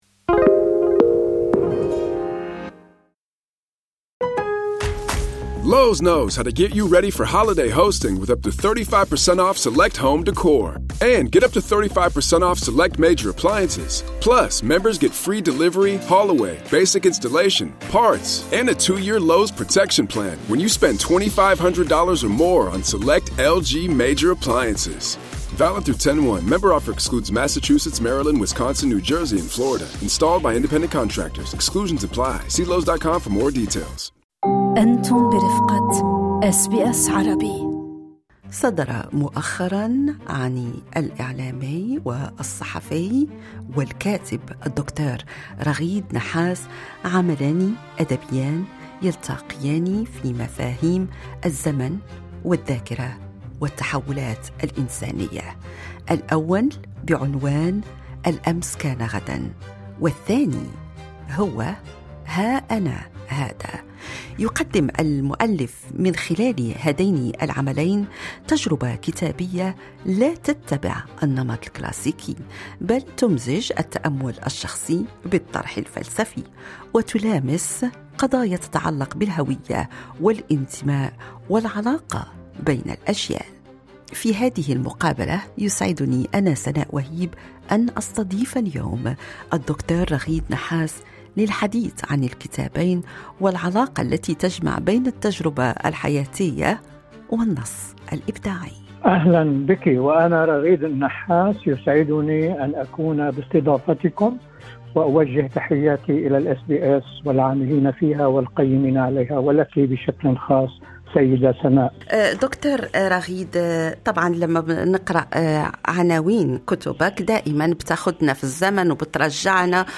المزيد في اللقاء أعلاه.